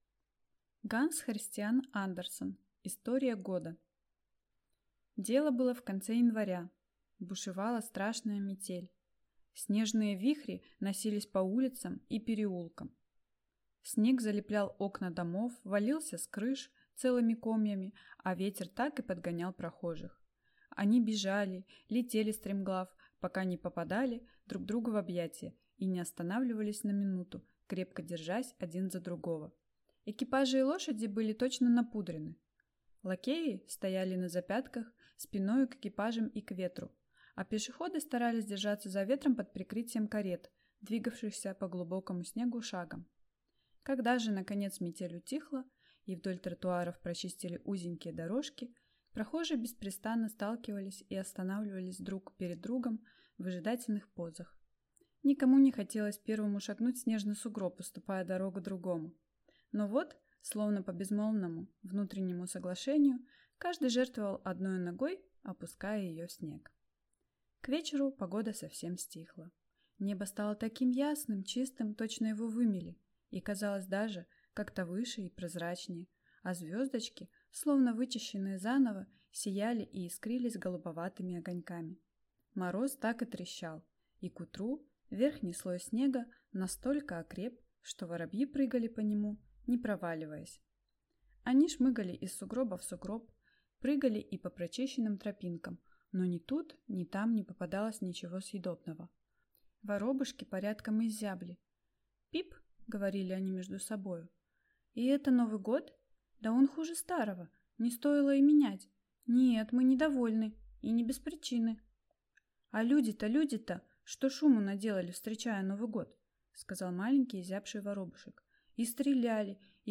Аудиокнига История года | Библиотека аудиокниг